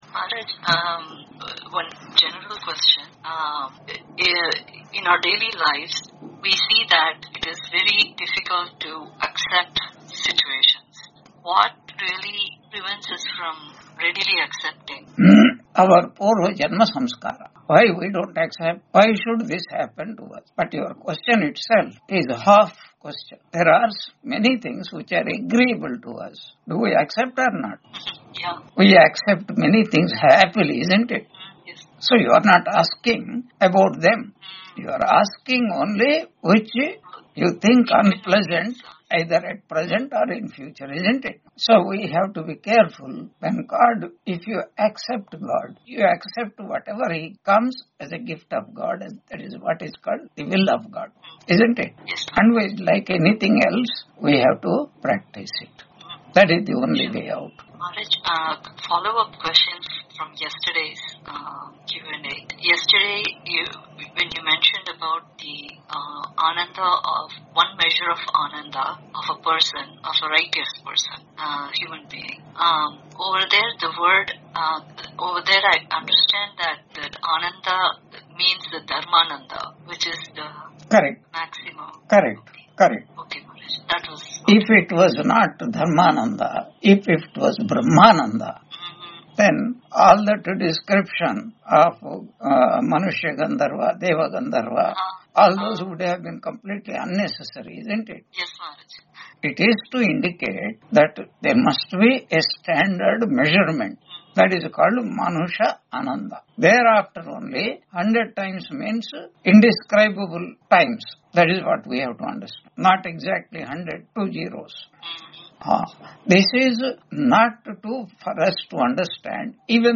Chandogya Upanishad 5.3 Introduction Lecture 158 on 23 November 2025 Q&A - Wiki Vedanta